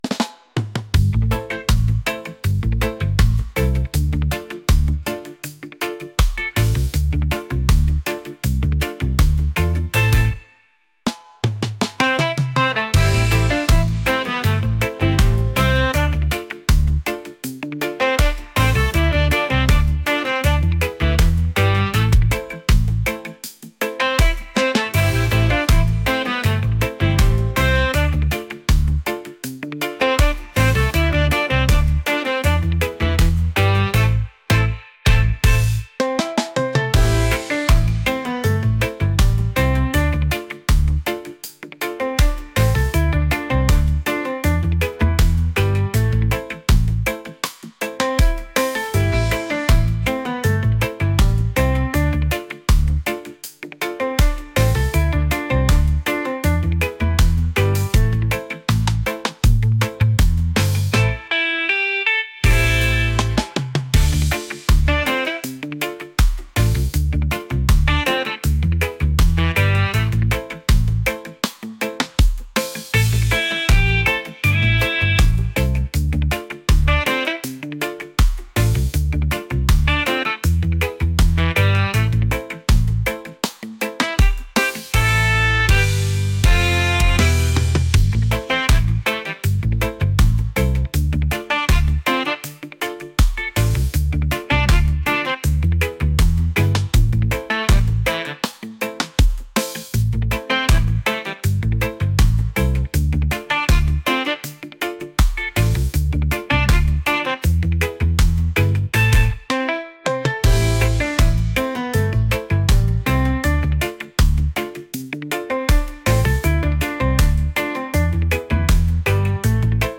reggae | groovy